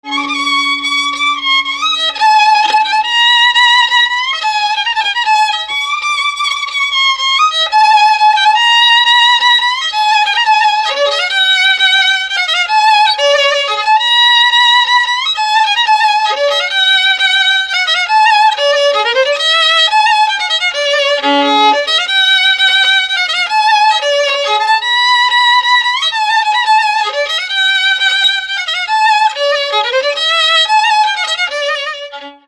Dallampélda: Hangszeres felvétel
Erdély - Alsó-Fehér vm. - Nagymedvés
hegedű Műfaj: Lassú csárdás Gyűjtő